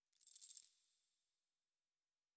03_鬼差脚步_3.wav